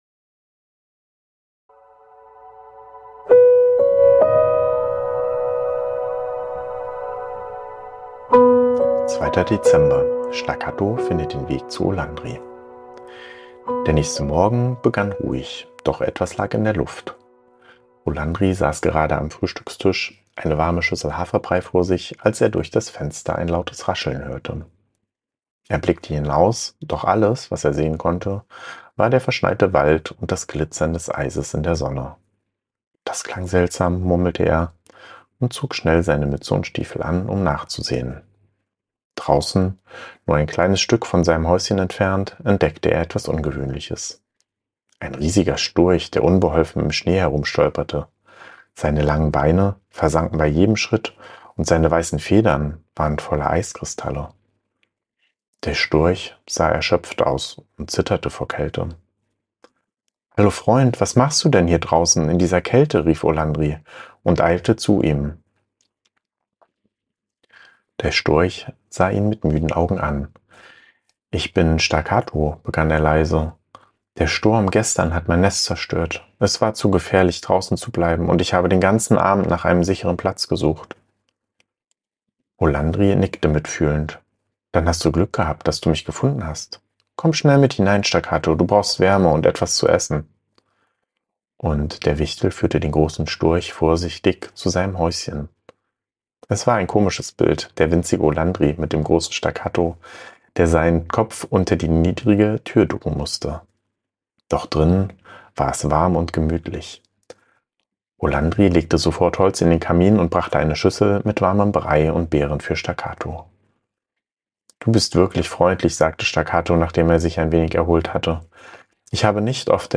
Ruhige Adventsgeschichten über Freundschaft, Mut und Zusammenhalt